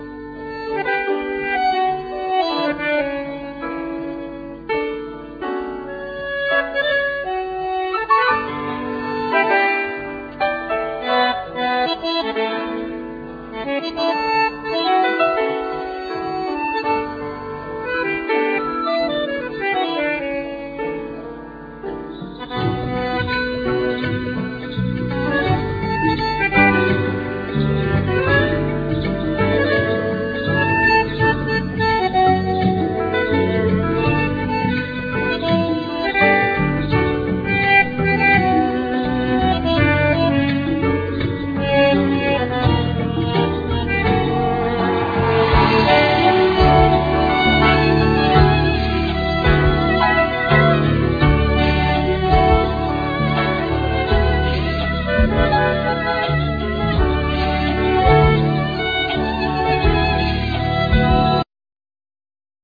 Bandneon,Glockenspiel
Piano,Synthsizer
Violin
Tenor saxophone
Drams,Tabla